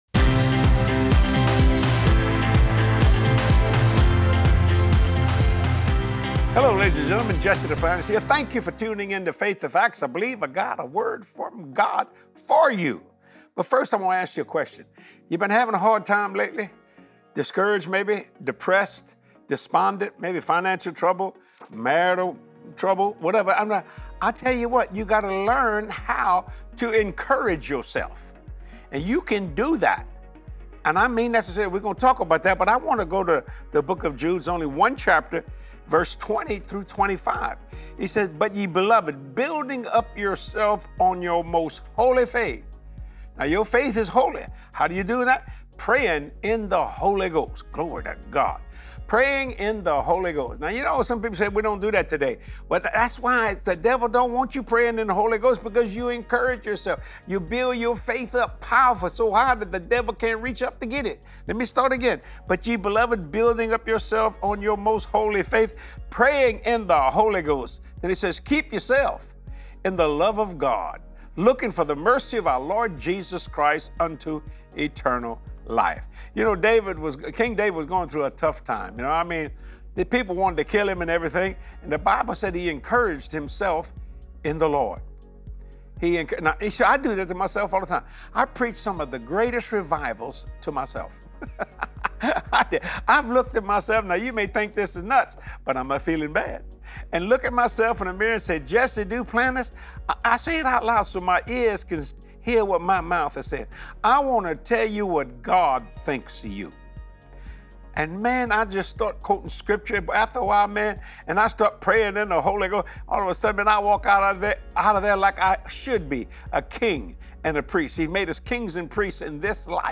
Build yourself up in your most holy faith! Choose encouragement today as you watch this faith building message from Jesse.